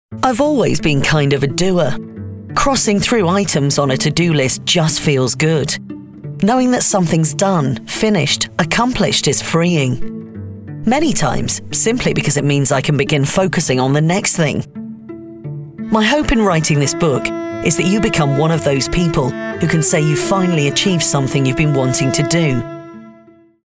Female
English (British)
Yng Adult (18-29), Adult (30-50)
Broadcast quality home studio with fast turnaround and many happy regular clients!
Audiobook Narration